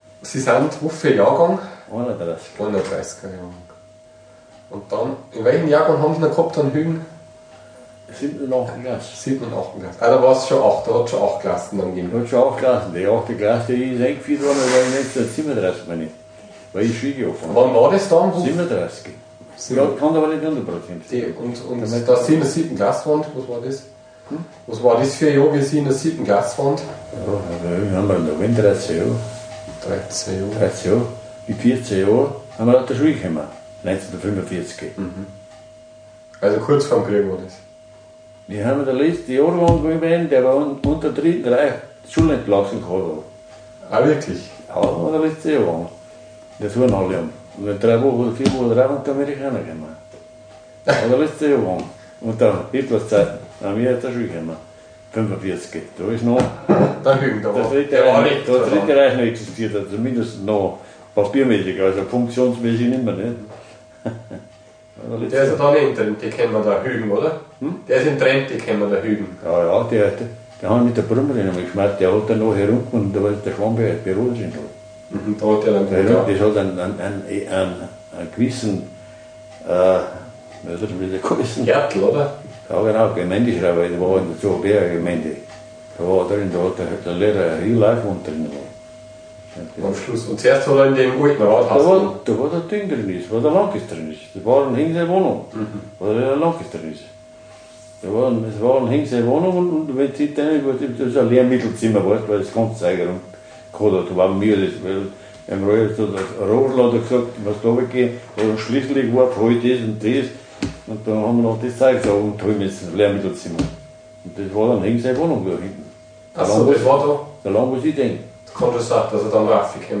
interview.m4a